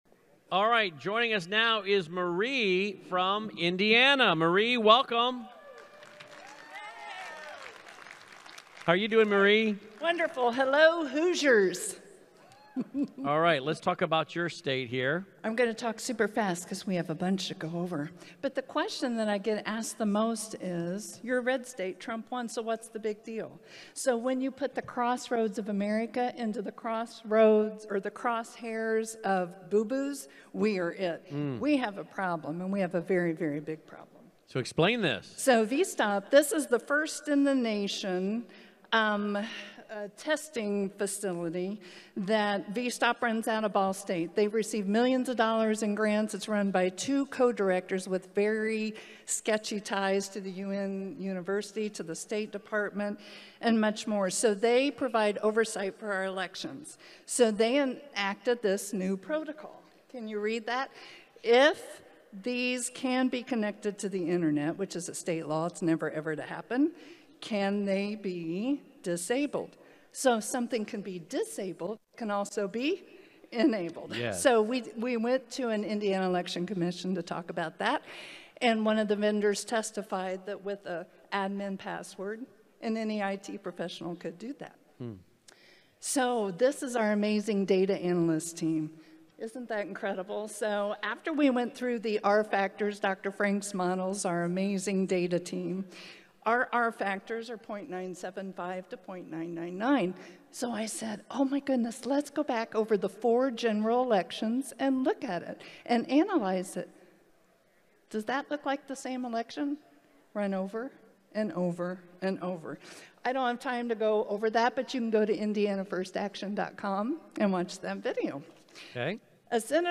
2022 IN Moment of Truth Summit State of the States Presentation audio – Cause of America